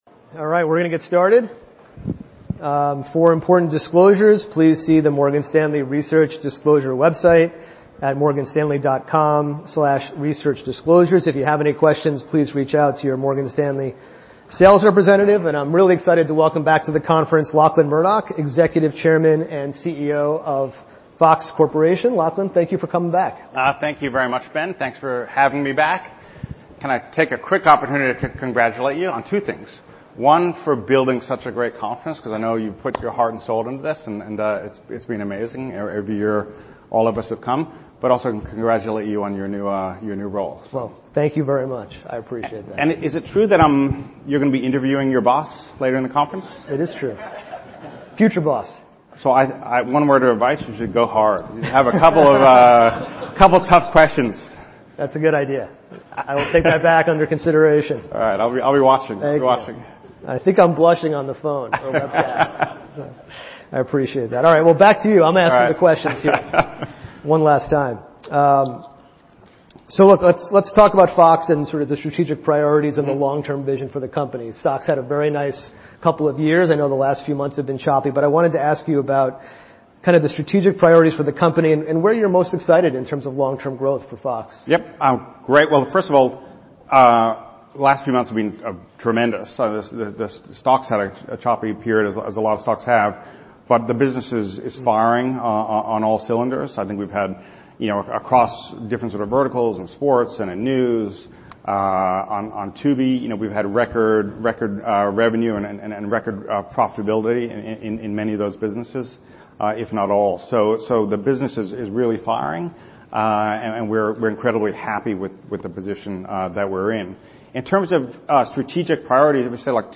EXECUTIVE CHAIR AND CHIEF EXECUTIVE OFFICER LACHLAN MURDOCH FIRESIDE CHAT